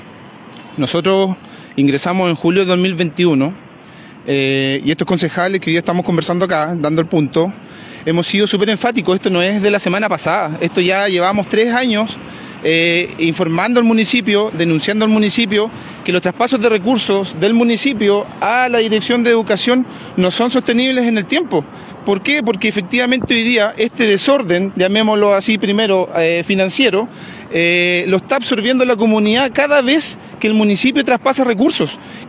Esta mañana en el frontis del Municipio de Puerto Montt, los concejales: Fernando Binder, Emilio Garrido, Yerco Rodríguez y Marcia Muñoz, realizaron un punto de prensa para referirse a los hechos registrados la tarde de ayer respecto a la detención de funcionarios del DAEM.
El concejal Fernando binder, puntualizó que desde hace mucho tiempo vienen cuestionando el desorden financiero de la actual administración edilicia.